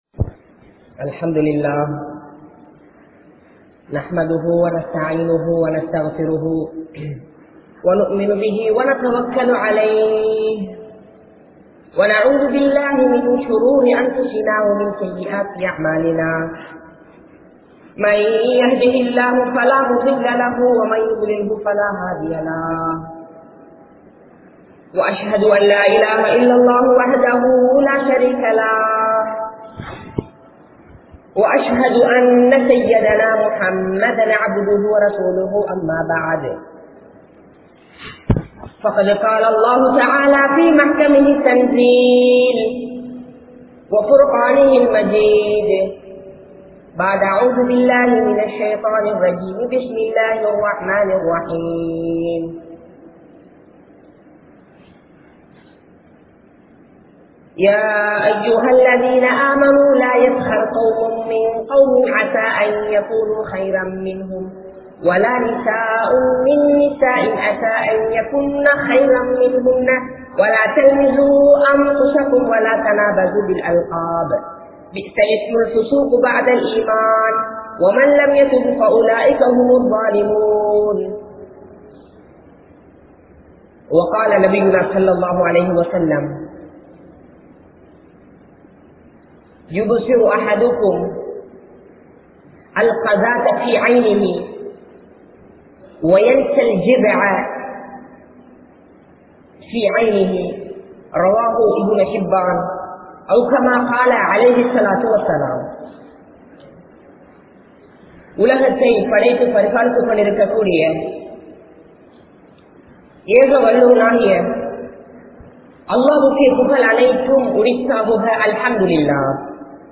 Puram Peasatheerhal (புறம் பேசாதீர்கள்) | Audio Bayans | All Ceylon Muslim Youth Community | Addalaichenai